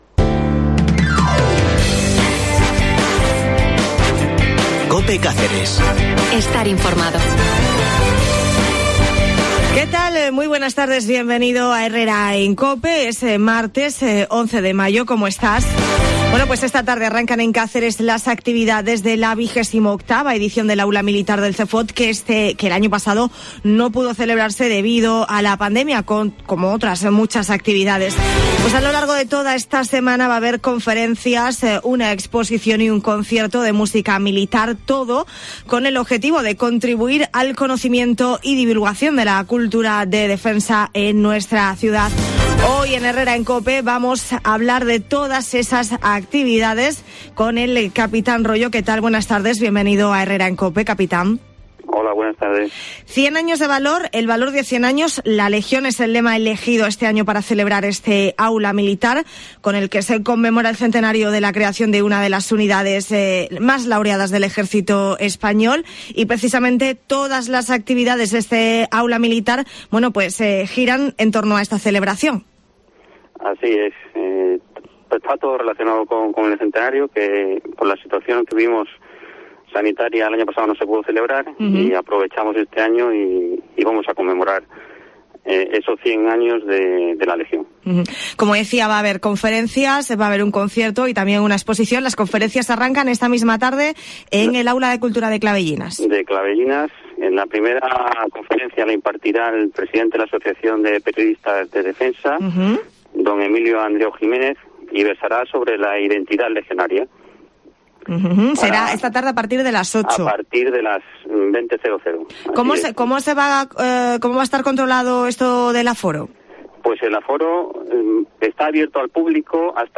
Hoy en Herrera en Cope Cáceres hemos hablado de todas esas actividades